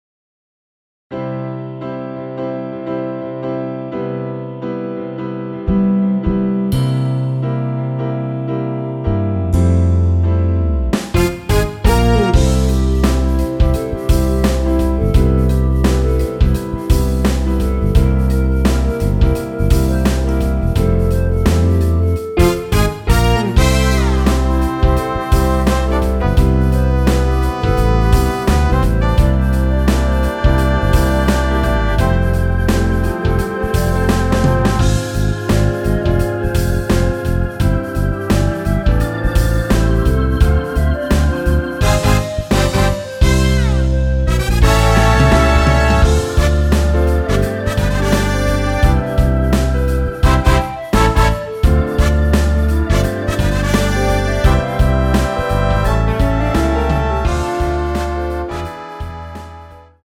원키에서(-1)내린 멜로디 포함된 MR입니다.
앞부분30초, 뒷부분30초씩 편집해서 올려 드리고 있습니다.
중간에 음이 끈어지고 다시 나오는 이유는